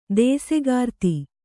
♪ dēsegārti